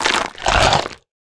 sounds / mob / camel / eat4.ogg
eat4.ogg